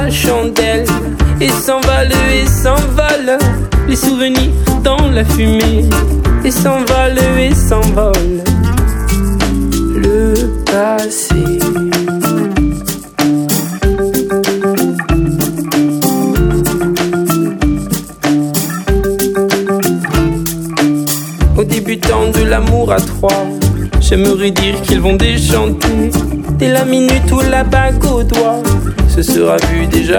"enPreferredTerm" => "Chanson francophone"